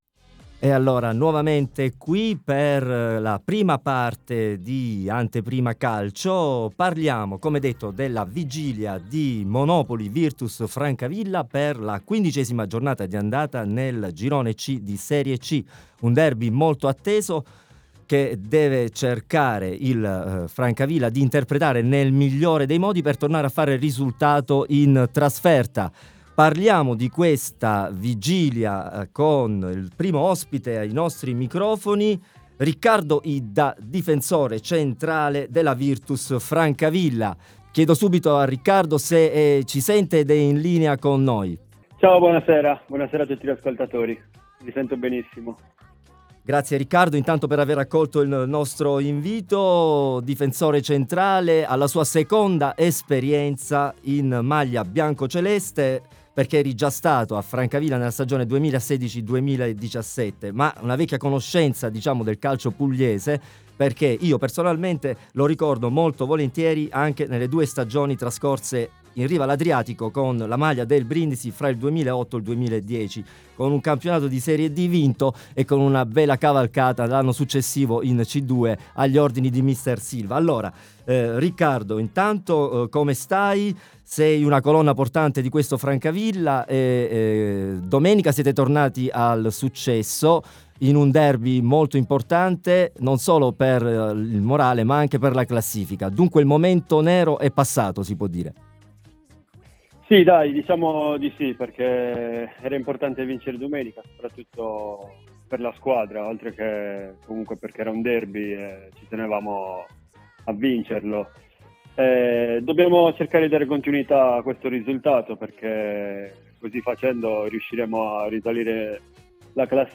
Ospite telefonico di questa puntata